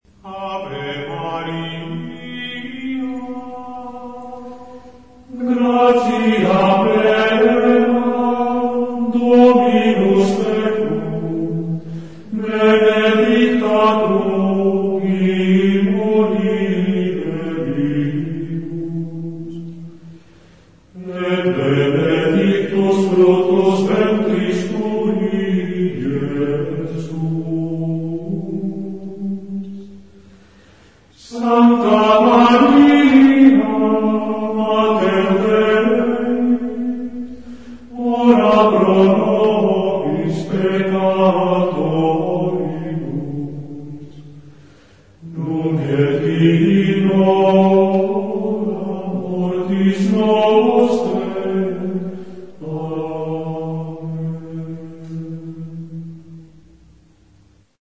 Canto Gregoriano